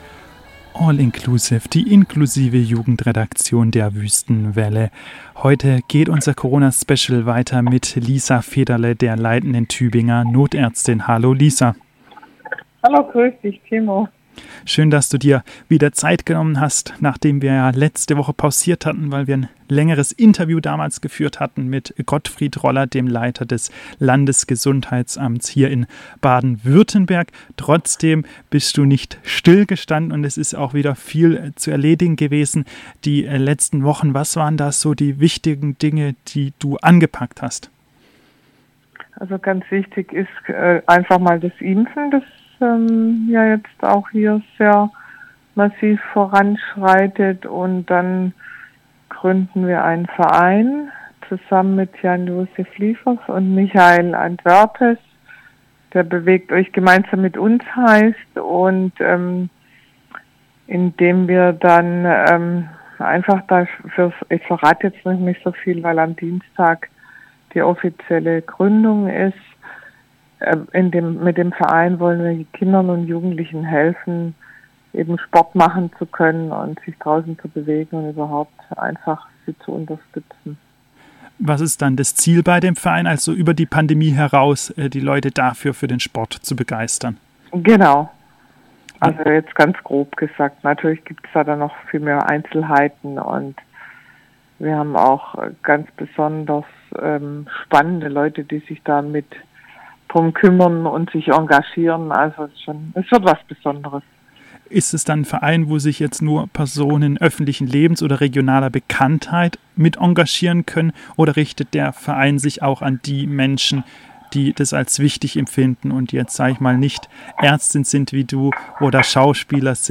Nun wird am kommenden Dienstag ein Verein gegründet, gemeinsam mit Schauspieler Jan Josef Liefers und Fernsehmoderator Michael Antwerpes, dies teilte die Ärztin im Telefoninterview mit.